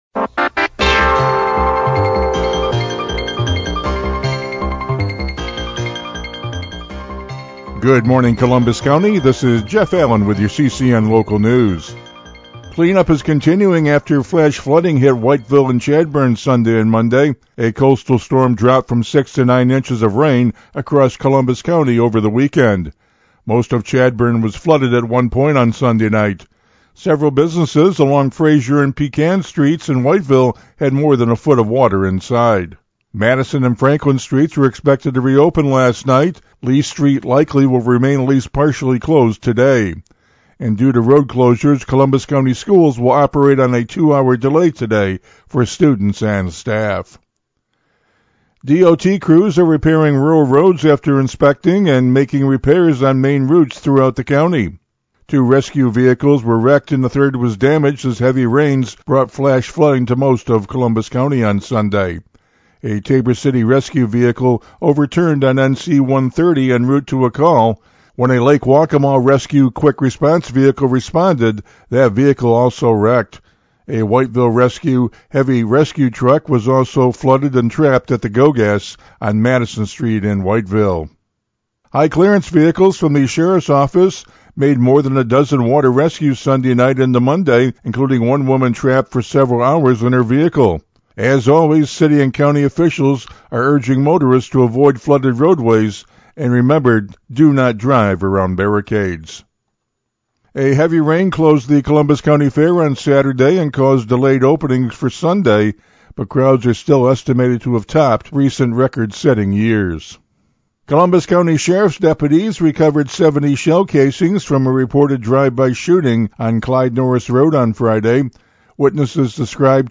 CCN Radio News — Morning Report for October 14, 2025